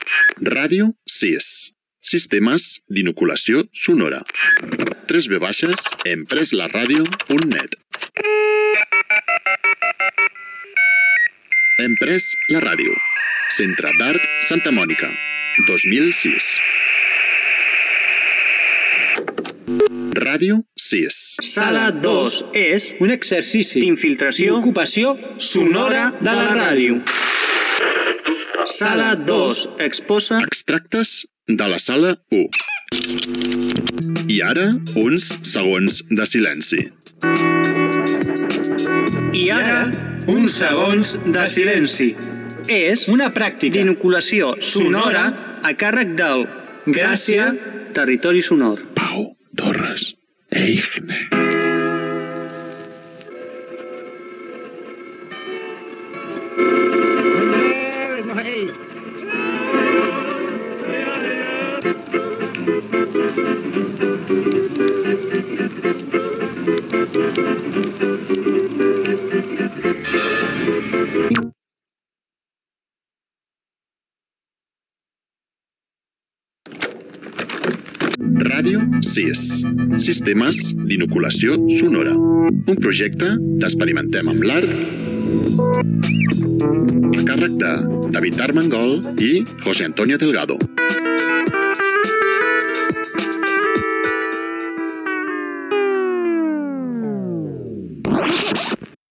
Identificació del programa i del projecte Hem pres la ràdio. Espai dedicat a la creació "La peonza". Careta de sortdia